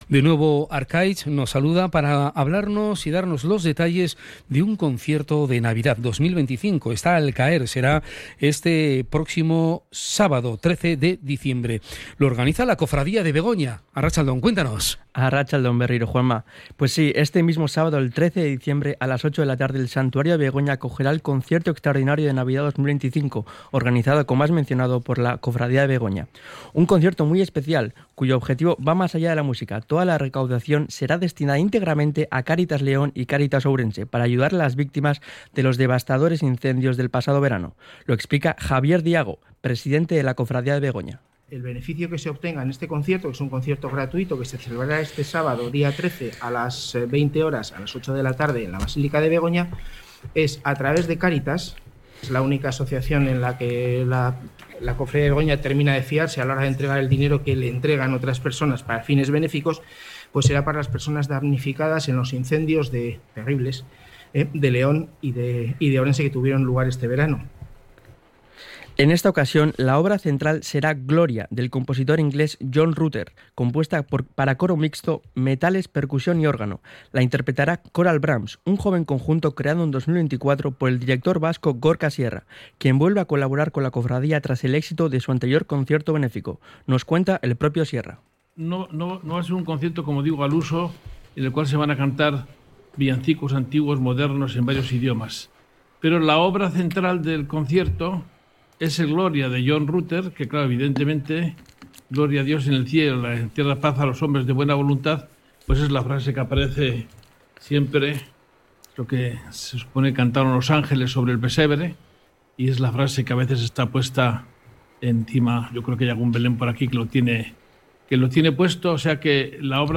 Rueda de prensa del Concierto de Navidad 2025 organizado por la Cofradía de Begoña / RADIO POPULAR - HERRI IRRATIA